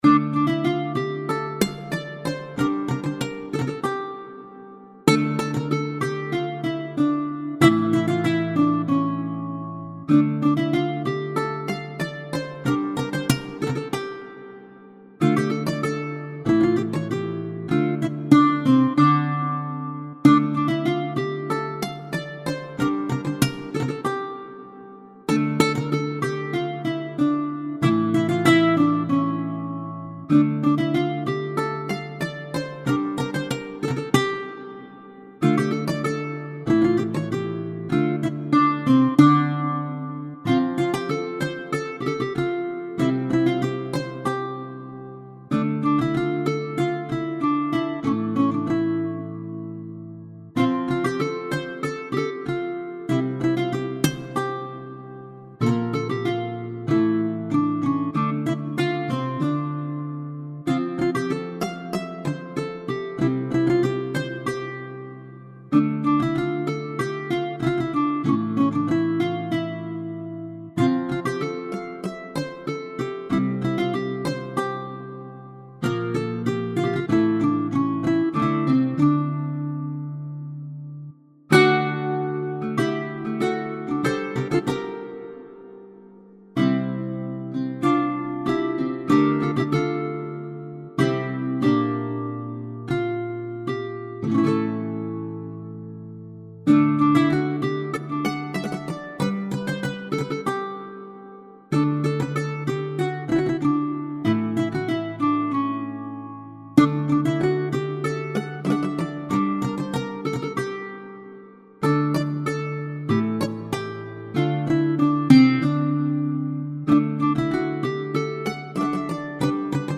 guitare - harpe - aerien - folk - melodieux